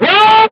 Vox (YeSession).wav